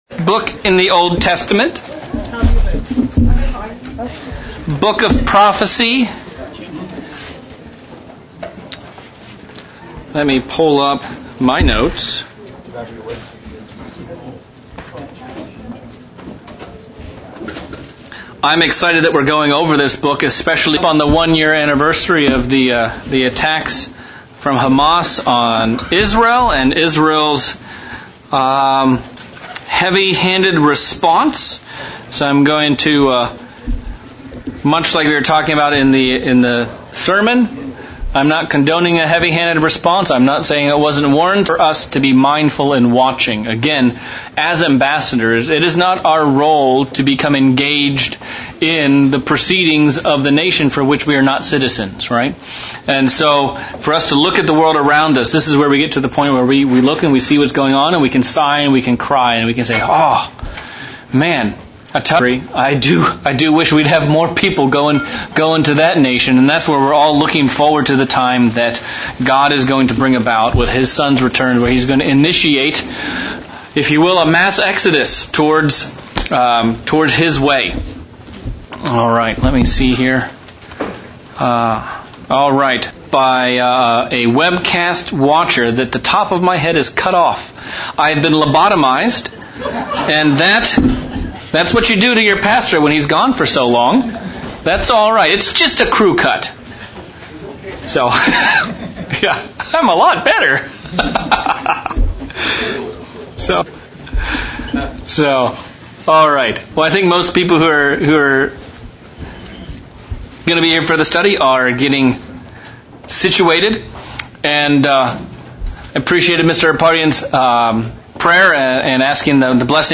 August 2024 Bible study - Starting the book of Obadiah with an introduction to conflict between Jacob and Esau and how it factors into the story of this book